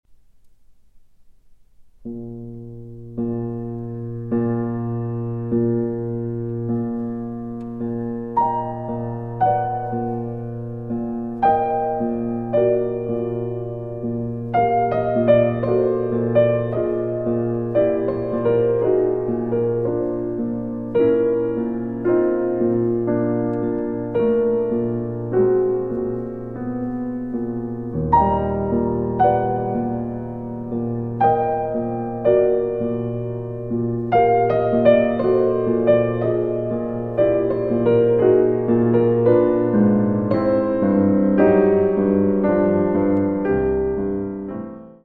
Larghetto